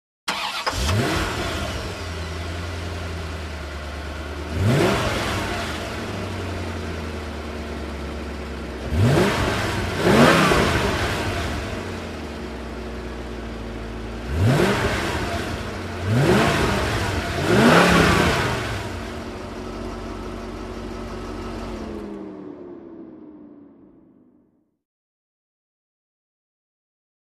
Corvette; Start / Idle / Off; Ignition, Smooth Start. Revs With Whine, Fan Hum, And Medium-speed Rhythmic Belt Noise. Off With Ventilation Shutdown. Close Perspective. Sports Car, Auto.